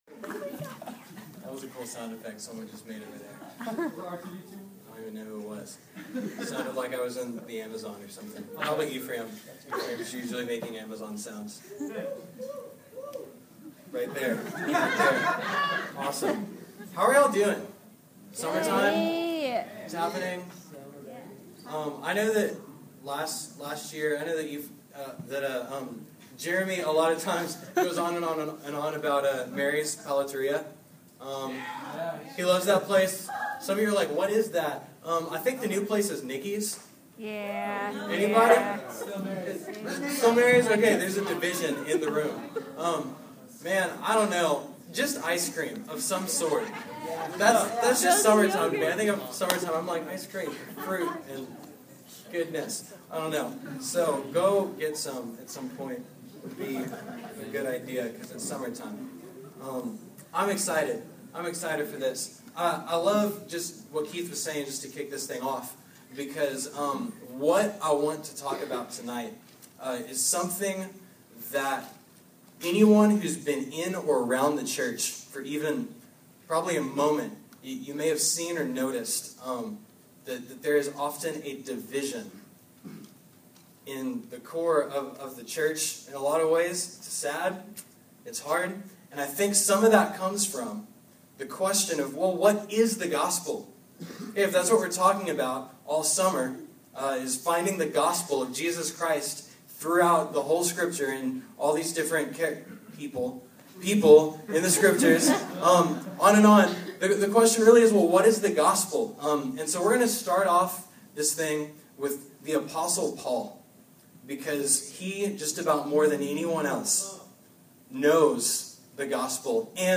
During the summer of 2012 I contributed to a sermon series about various Bible characters and the gospel with the college ministry of Beltway Park Church. I began the series by looking at the life and teachings of the apostle Paul.